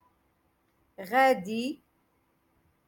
Moroccan Dialect- Rotation Six - Lesson Two Three